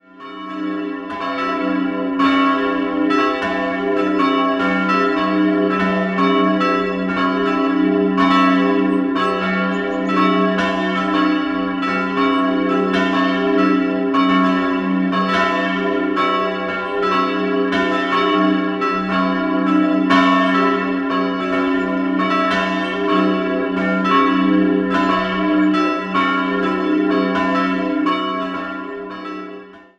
3-stimmiges Geläut: e'(+)-cis''-e'' Die große Glocke wurde um das Jahr 1500 in Nürnberg gegossen, die anderen wurden im 13./14. Jahrhundert gegossen.